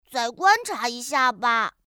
ask_error.mp3